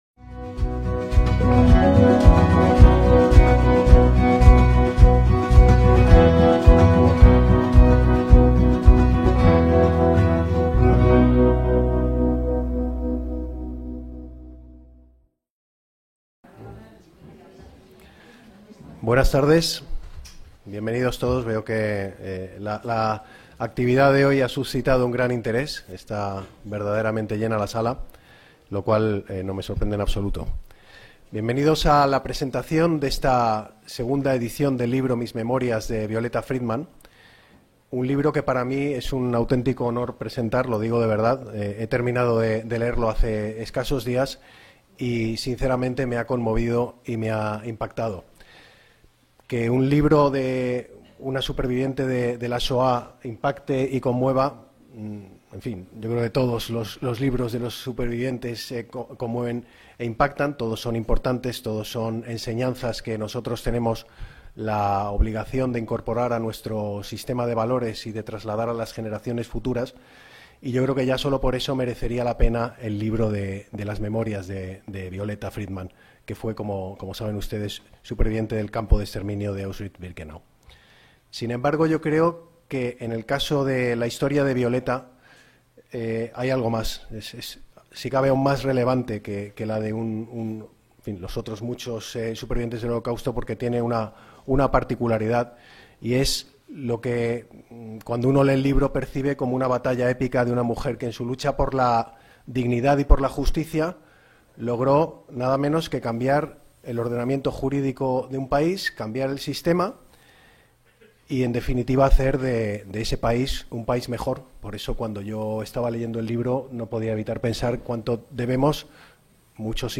ACTOS EN DIRECTO - Centro Sefarad-Israel, la editorial Nagrela y la Fundación Violeta Friedmann presentaron el día 28 de octubre de 2025 el libro "Mis memorias", la conmovedora autobiografía de Violeta Friedman, superviviente de la Shoah que vivió en Madrid y mostró una valentía ejemplar al enfrentarse al nazi León Degrelle.